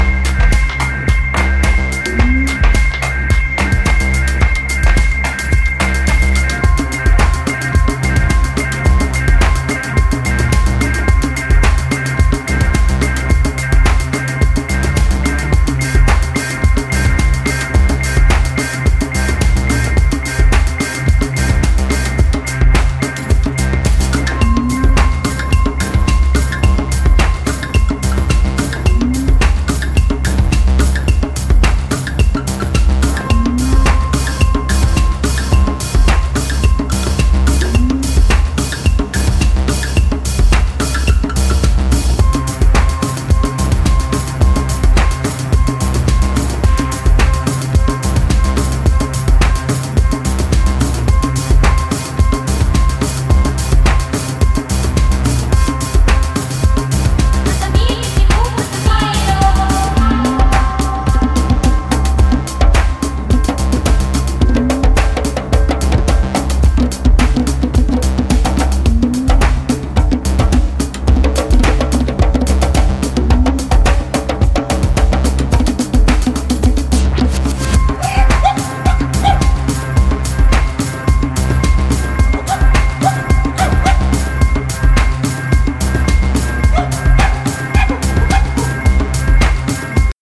個性的なスロー・トライバル〜ワールド・ミュージックっぽいオリジナルをスタイリッシュにクラブ・トラック化！